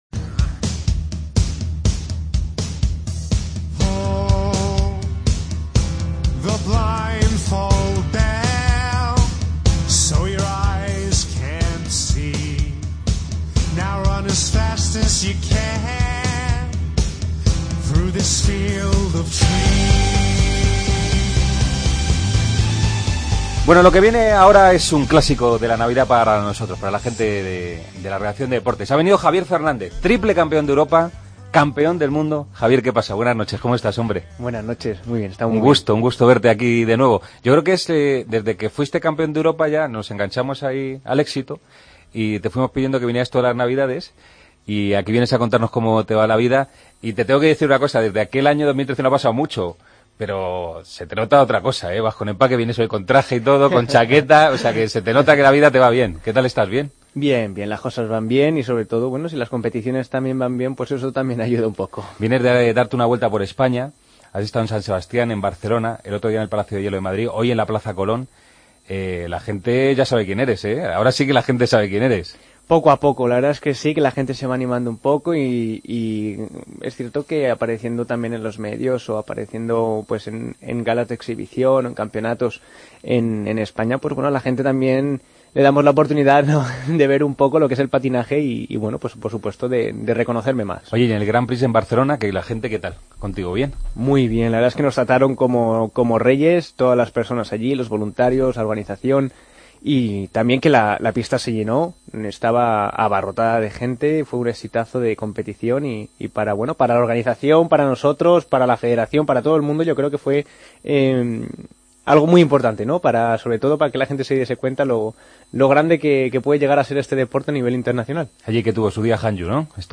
AUDIO: Entrevista al patinador Javier Fernández. La agenda del día.